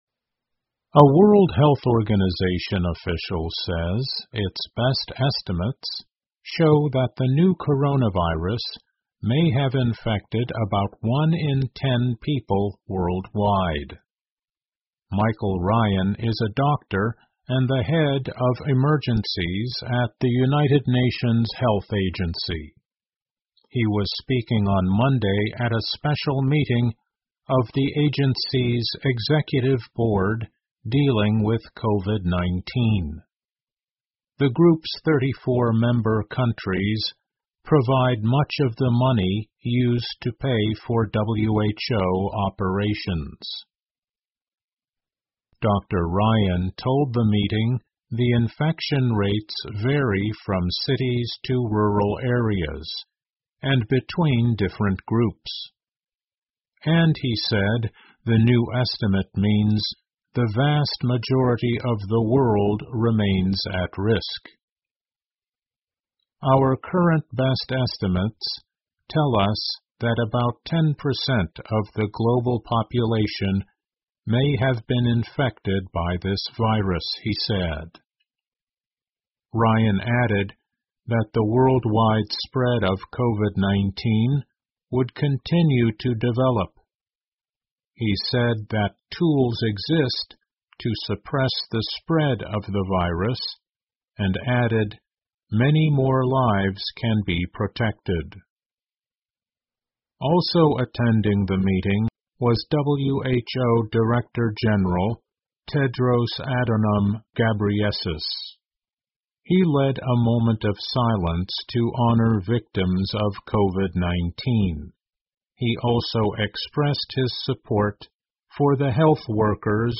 VOA慢速英语 听力文件下载—在线英语听力室